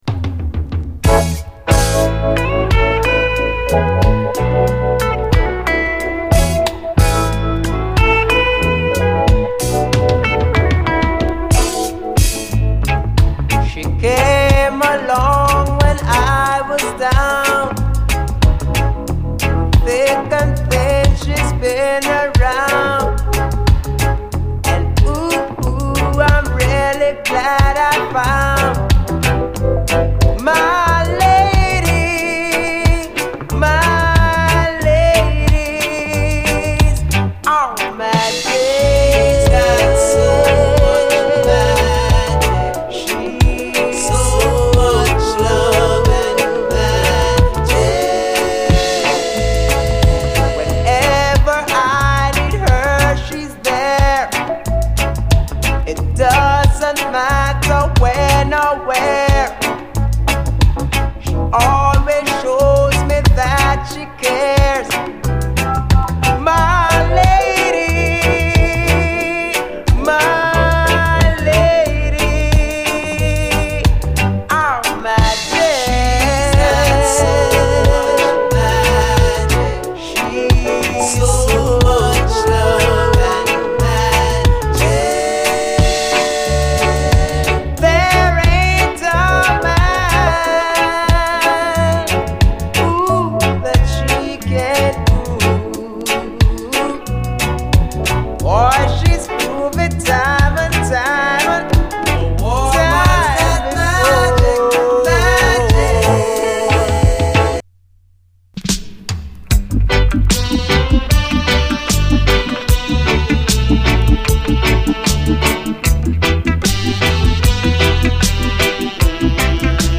フレンチ・ディスコを中心に、70’S当時のユーロ・ディスコ・ヒットをコンパイルした、フランスのオムニバス盤！